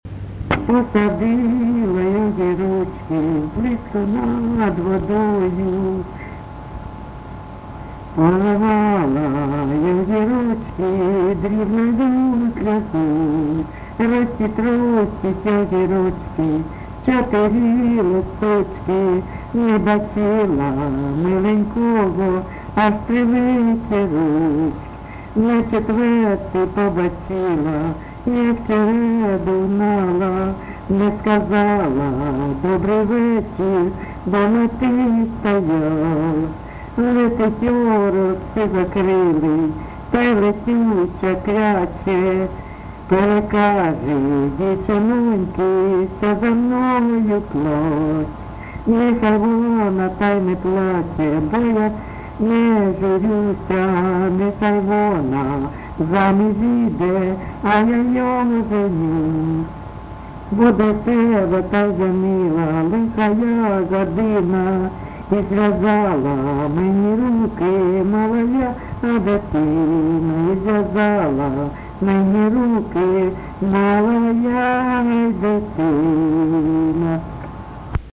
ЖанрПісні з особистого та родинного життя
Місце записус. Привілля, Словʼянський (Краматорський) район, Донецька обл., Україна, Слобожанщина